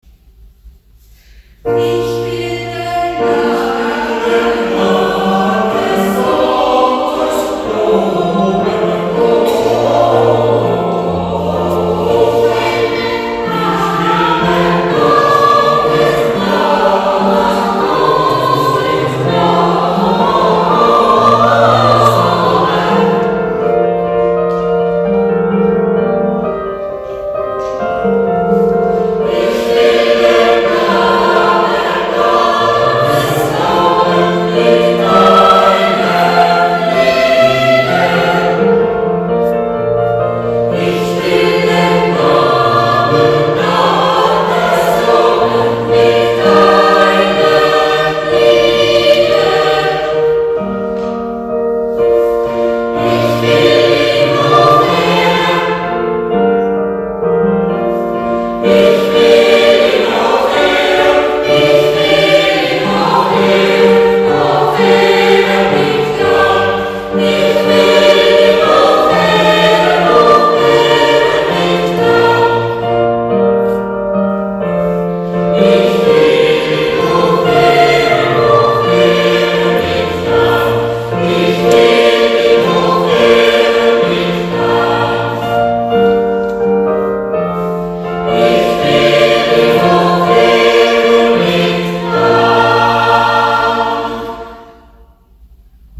In insgesamt 9 Proben üben wir bekannte, aber auch weniger bekannte, mehrstimmige Weihnachtslieder ein. Am Weihnachtsgottesdienst vom 25.12.2025 gestaltet der Chor mit den Liedern den musikalischen Teil des Festgottesdienstes.